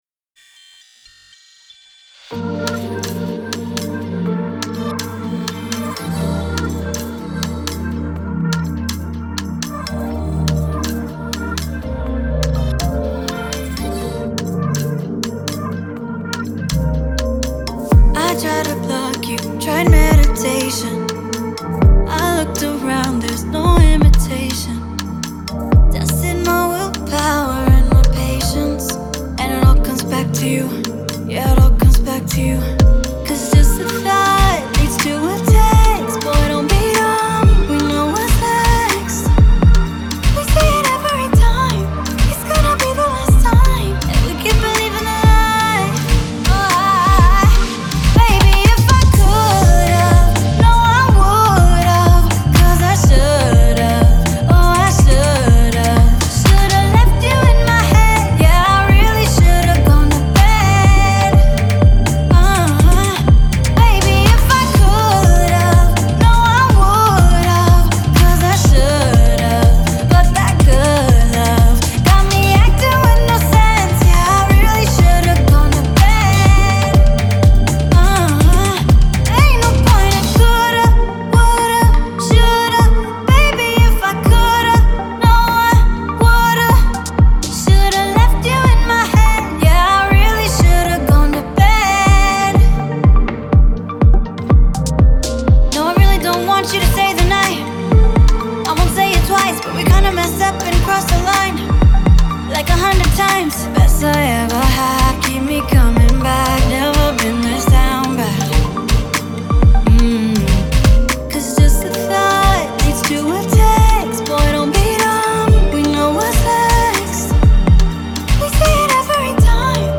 Hip Hop
features soothing atmospheres and tunes